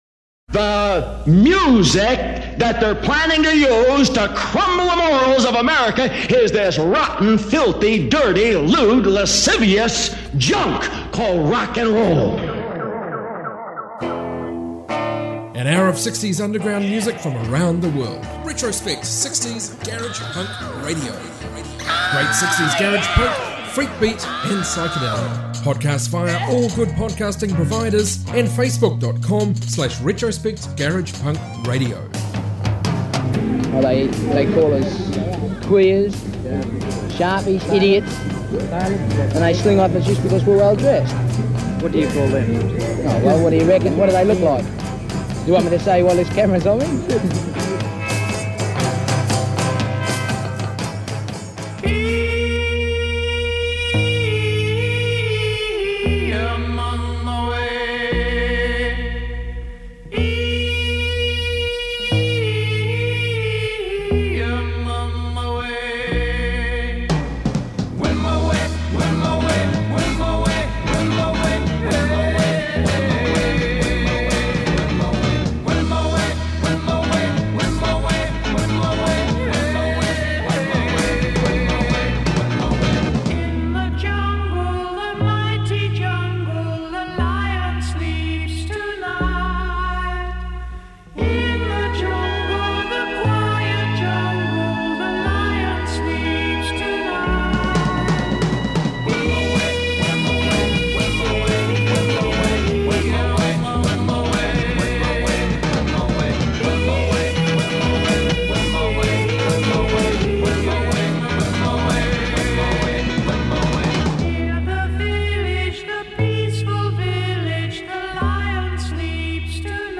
60s garage music